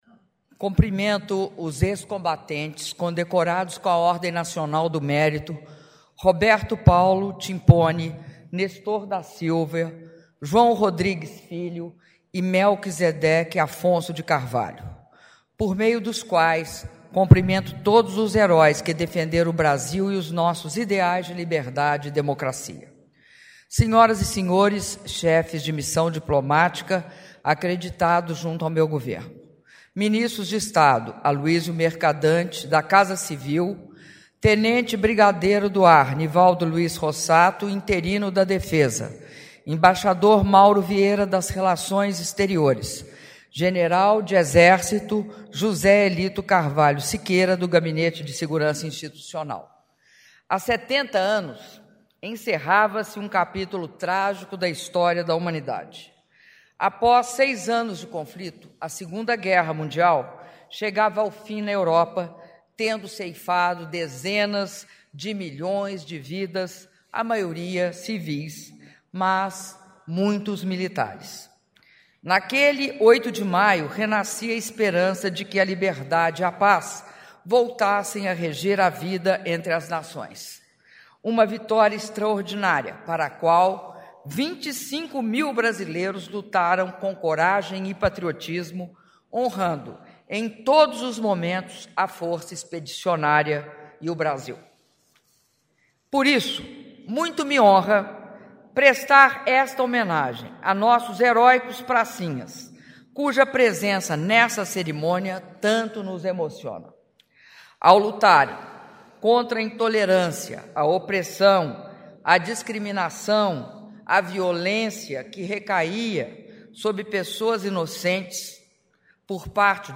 Áudio do discurso da Presidenta da República, Dilma Rousseff, após cerimônia de Comemoração dos 70 anos do Dia da Vitória - Brasília/DF - (04min31s)